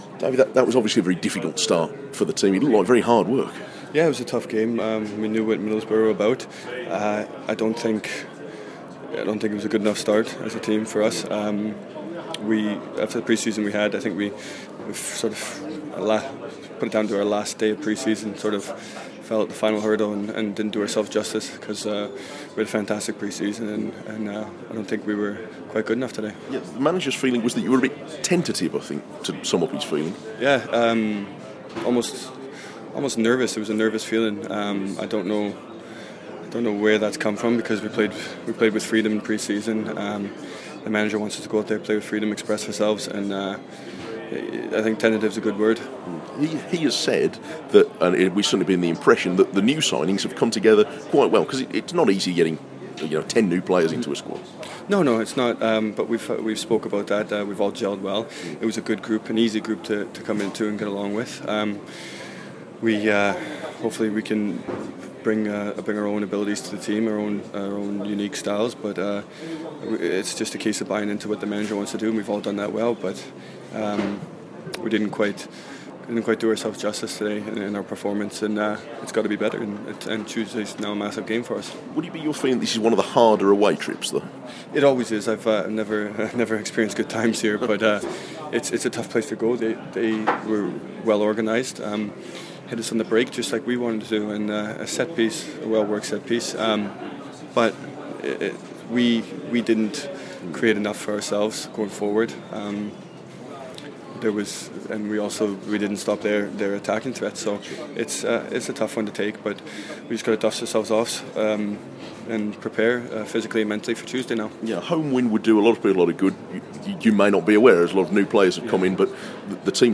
David Edgar talks to BBC WM post Middlesbrough